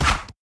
snd_dagger.ogg